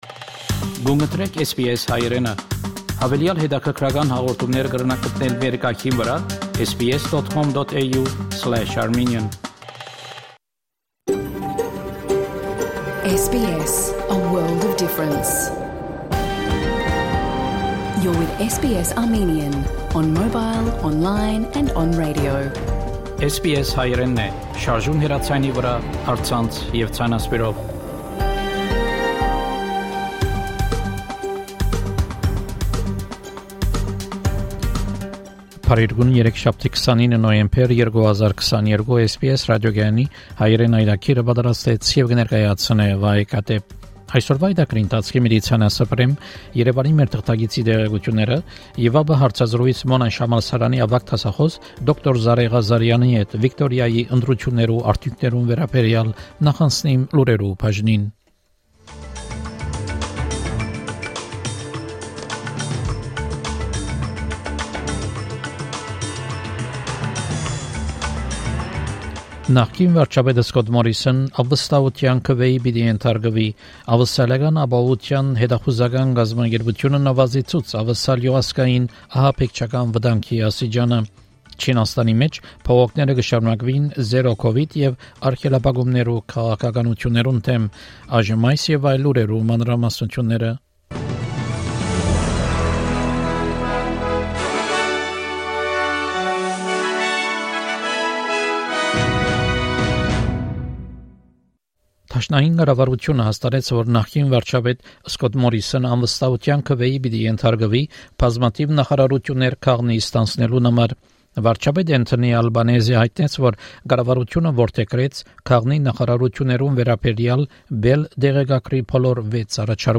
SBS Armenian news bulletin – 29 November 2022
SBS Armenian news bulletin from 29 November 2022 program.